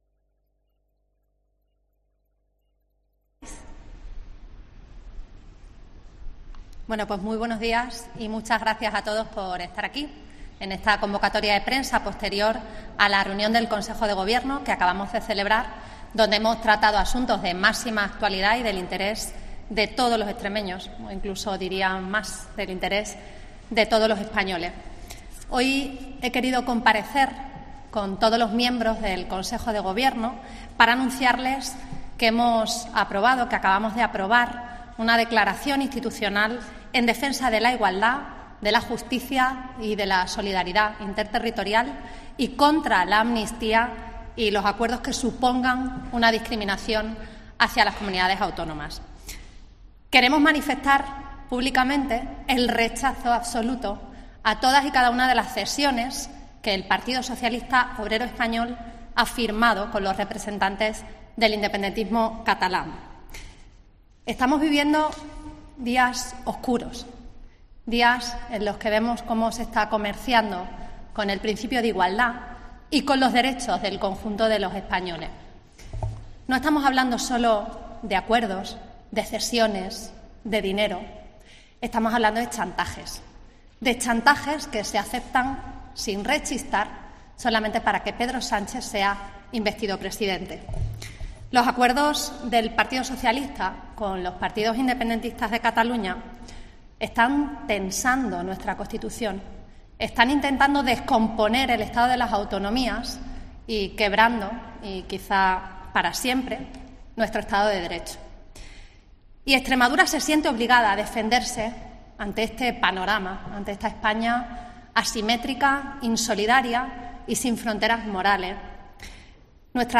Así lo ha indicado la presidenta extremeña, María Guardiola, en una rueda de prensa tras la reunión semanal de su gabinete, en la que ha comparecido acompañada por todos sus miembros para dar cuenta de esta declaración con la que tratará de impulsar un frente común de todos los partidos con representación parlamentaria en la región.